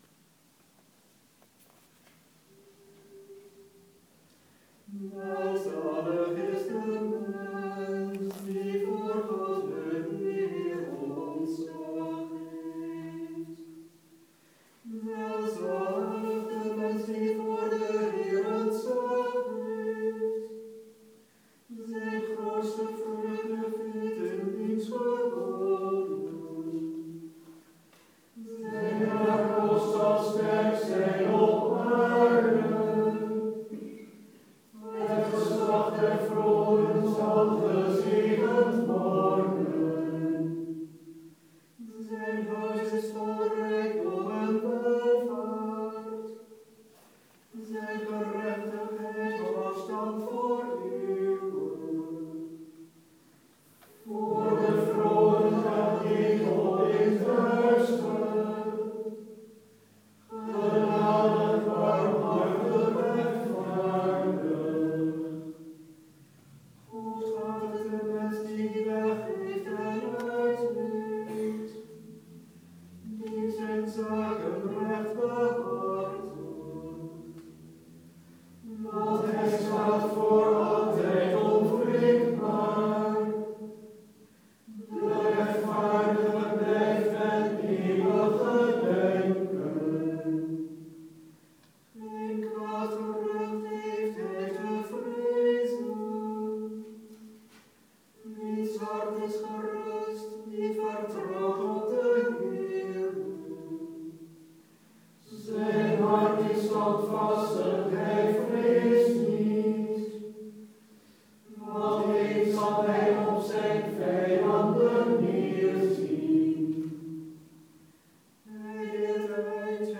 De dag werd om 17:00 uur afgesloten met een Vesper, in de dagkapel van de Dominicuskerk in Nijmegen (alwaar de Oud-Katholieke Kerngroep St. Stephanus iedere eerste zaterdag van de maand om 19:00 uur een viering houdt).